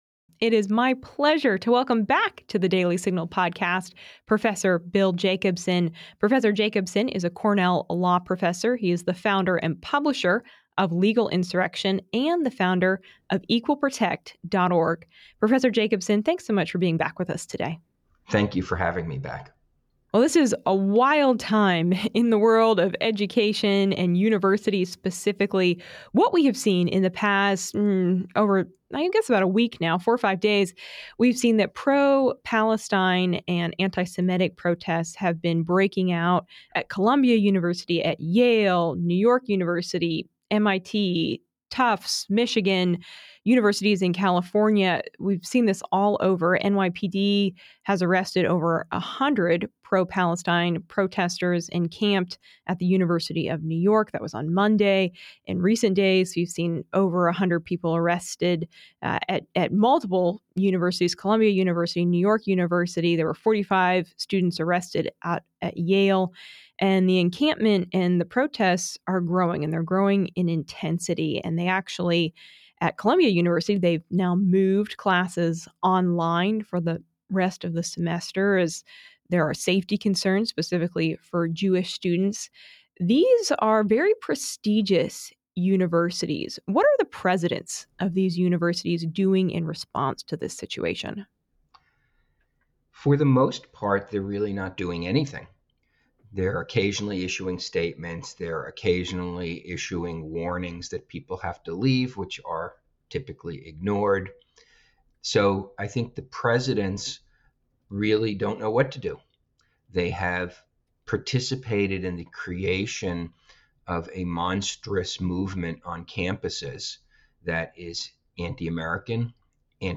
My interview on The Daily Signal Podcast